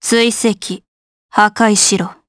Valance-Vox_Skill1_jp.wav